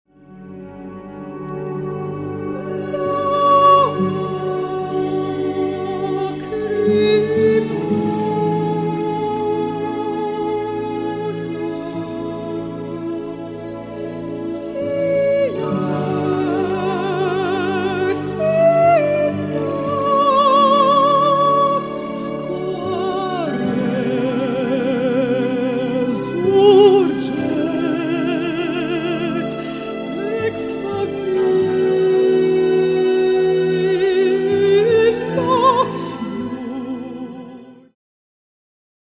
Mezzo-soprano